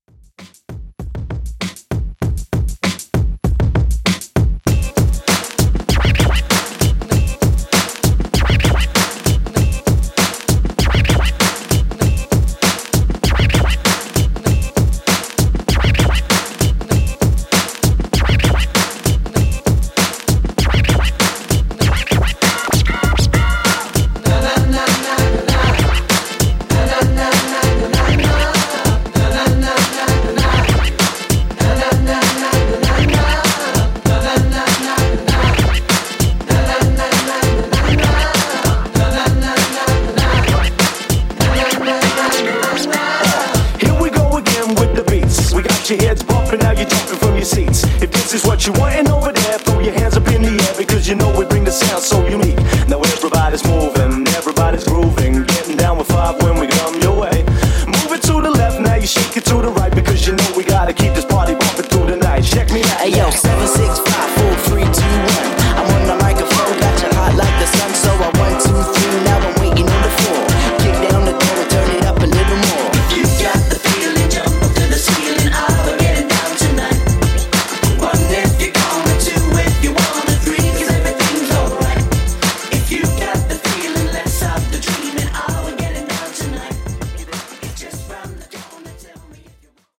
90s Redrum)Date Added